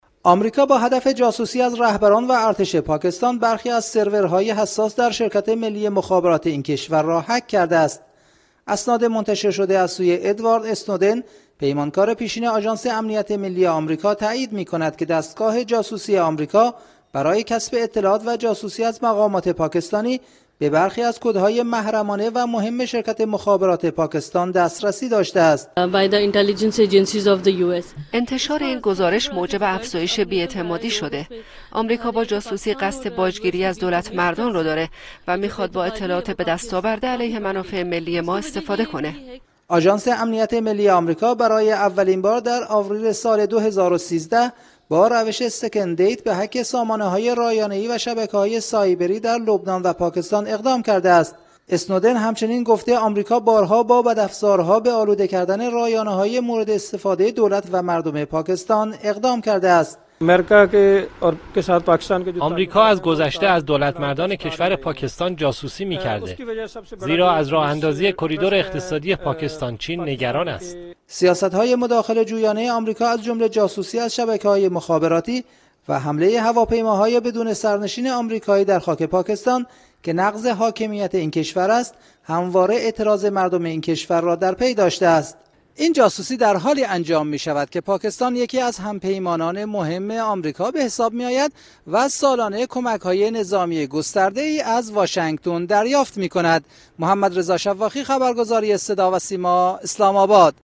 Ахбор